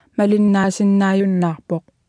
Below you can try out the text-to-speech system Martha.